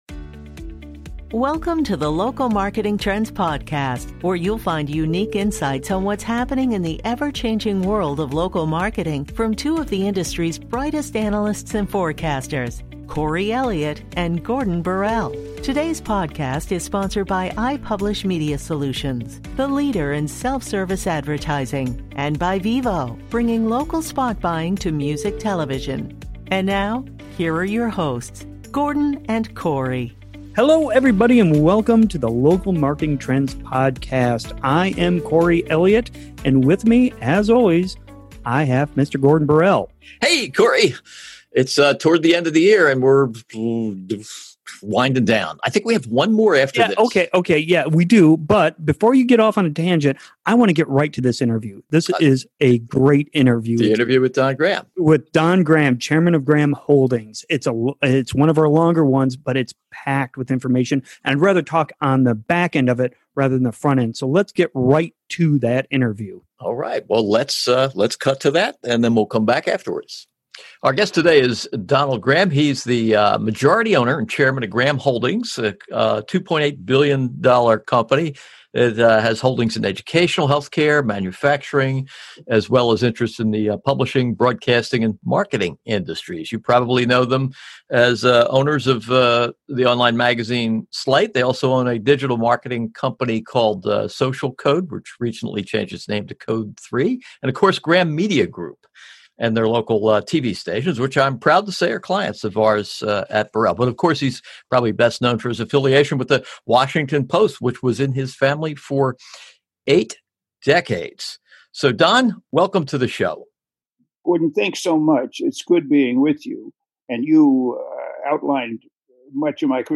Our penultimate podcast of 2020 features an interview with Donald Graham, chairman of Graham Holdings and former publisher of The Washington Post. Graham reflects on what led to the decision to sell the paper in 2013 after eight decades of family ownership, and what lessons today's print and broadcast media might learn from Bezos' handling of The Post.